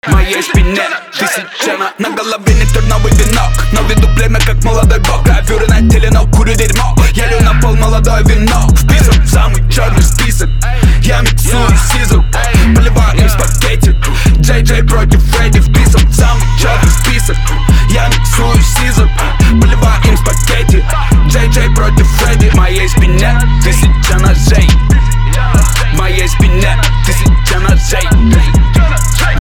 • Качество: 320, Stereo
громкие
русский рэп
басы
качающие
злые
Стиль: Trap